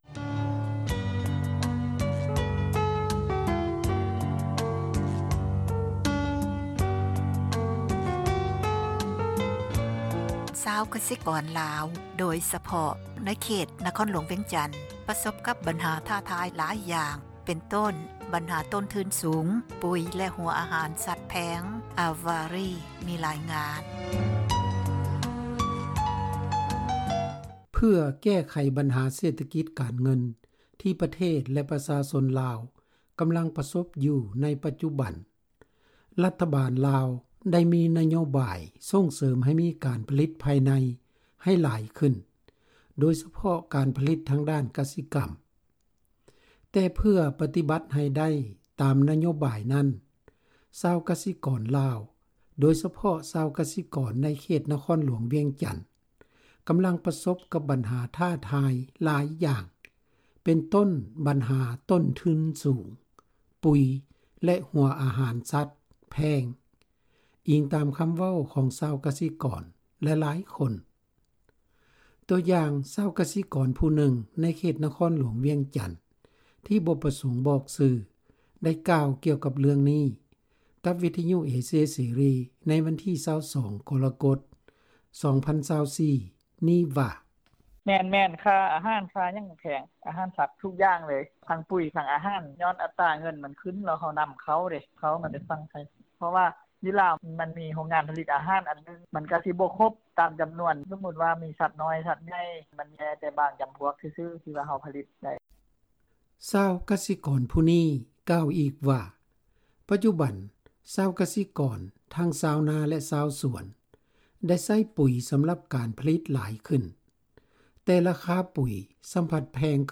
ຕົວຢ່າງ ຊາວກະສິກອນຜູ້ນຶ່ງໃນເຂດນະຄອນຫຼວງວຽງຈັນ ທີ່ບໍ່ປະສົງບອກຊື່ ໄດ້ກ່າວກ່ຽວກັບເລື່ອງນີ້ກັບວິທະຍຸເອເຊັຍເສຣີໃນວັນທີ່ 22 ກໍລະກົດ 2024 ນີ້ວ່າ:
ໃນຂະນະດຽວກັນ ຊາວກະສິກອນອີກຜູ້ໜຶ່ງຈາກນະຄອນຫຼວງວຽງຈັນ ກໍຢືນຢັນວ່າ ຊາວກະສິກອນລາວໃນທົ່ວປະເທດ ກໍຄືຊາວກະສິກອນໃນເຂດນະຄອນຫຼວງວຽງຈັນ ລ້ວນແລ້ວແຕ່ປະສົບກັບບັນຫາຕົ້ນທຶນສູງ ແລະບັນຫານ້ຳມັນ ປຸຍແລະຫົວອາຫານສັດແພງຂຶ້ນທຸກໆມື້ ດັ່ງຊາວກະສິກອນຜູ້ນີ້ ໄດ້ກ່າວວ່າ: